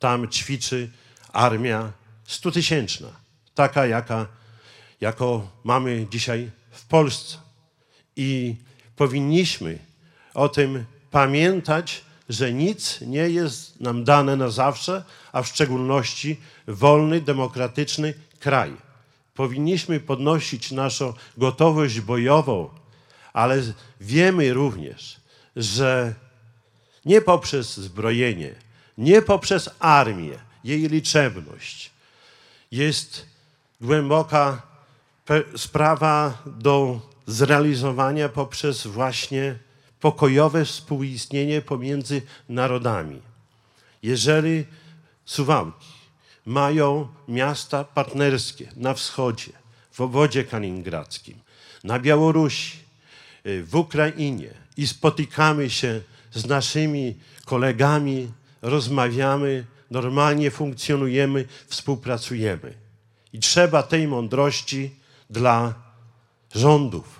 Po mszy świętej w kościele pod wezwaniem świętych Piotra i Pawła głos zabrał Czesław Renkiewicz, prezydent Suwałk.